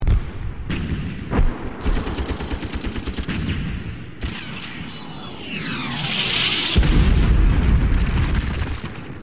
war sounds
Category: Sound FX   Right: Personal